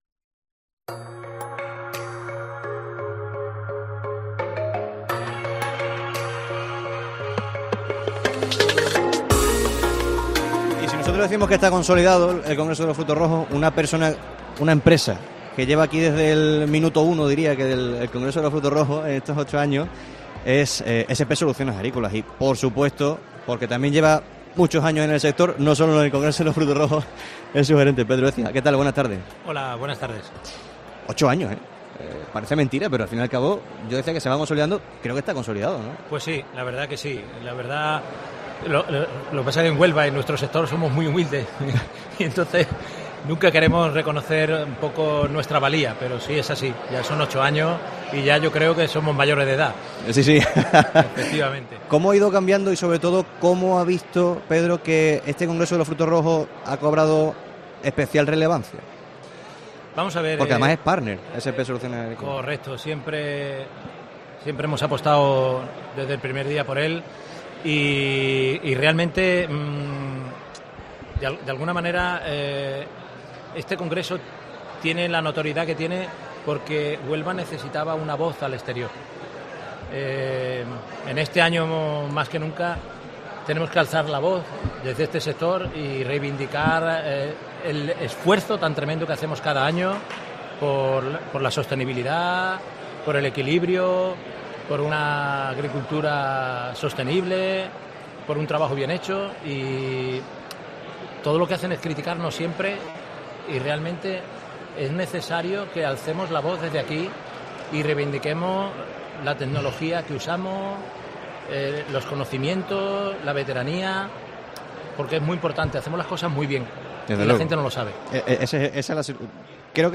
Herrera en COPE Huelva con SP Soluciones Agrícolas desde el Congreso Internacional de los Frutos Rojos